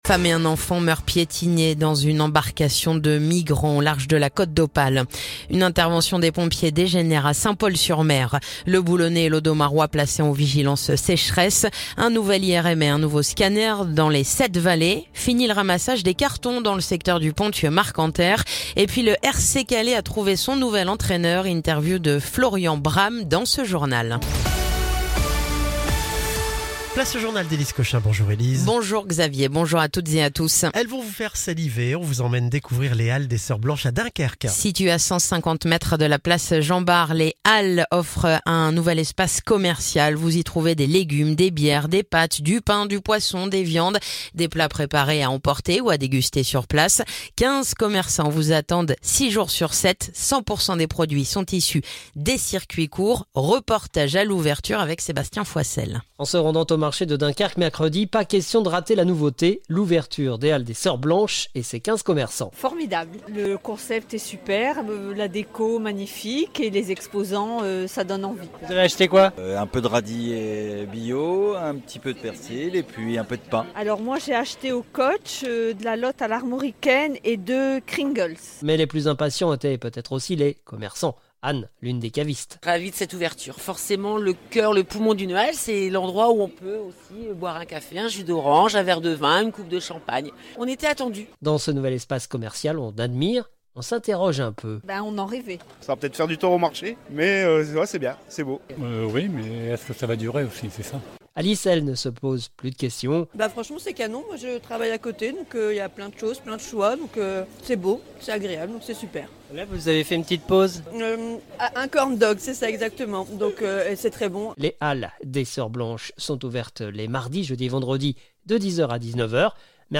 Le journal du jeudi 22 mai